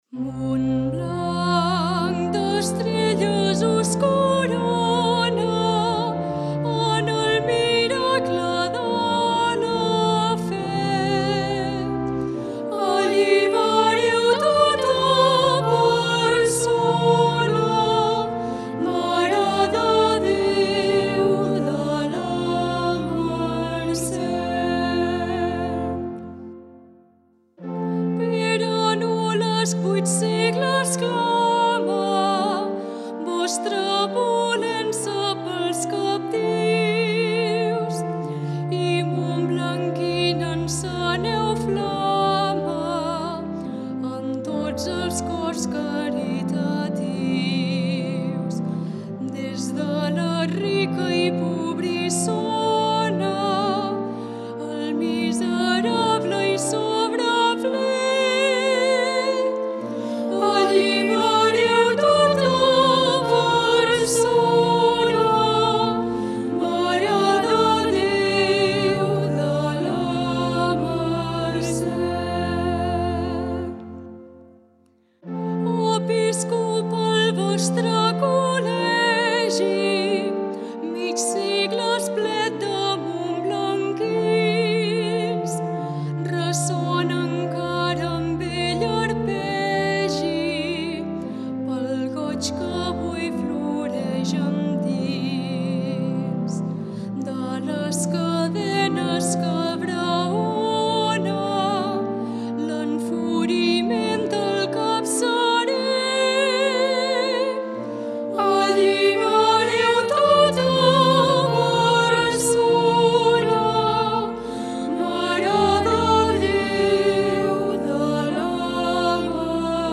A continuació trobareu diverses músiques populars religioses de Montblanc digitalitzades amb motiu de l’Any de la Mare de Déu (setembre 2021- setembre 2022):